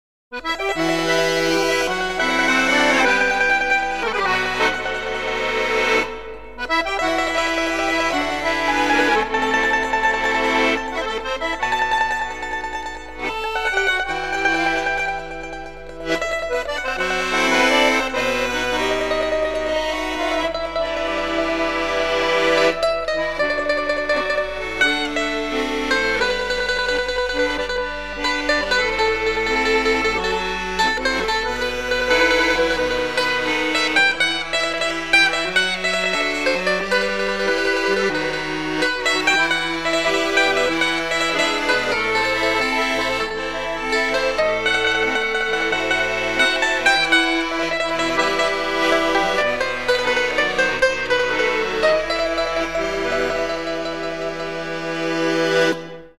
Pranzo/Cena - Fisarmonica & Mandolino